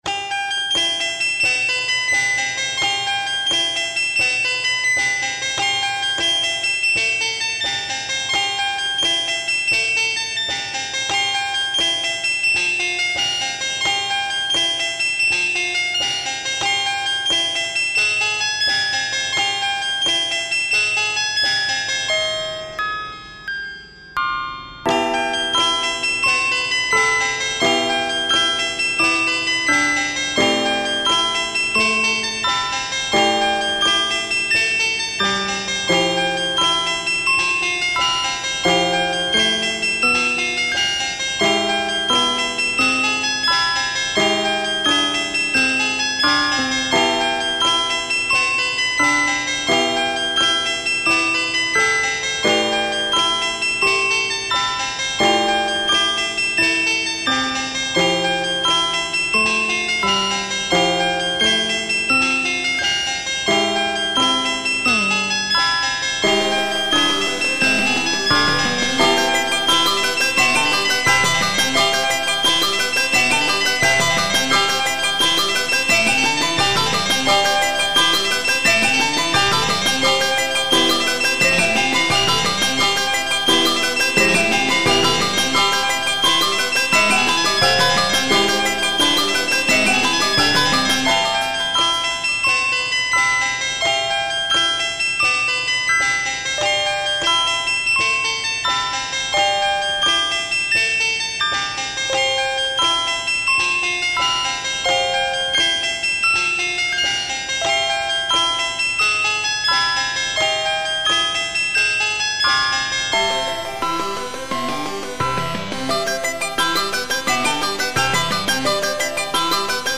I made my first (attempt at an) ambient piece.
in Piano Music, Solo Keyboard
for this, I used an old broken, and out of tune synth keyboard to give me an arpeggio, I played around with the notes switches to make it sound right. Then I had to record it, send it, download it, and sample it; I added some piano and the song was good.
It's supposed to sound off tune and off beat, to make it sound more janky and broken like the piano.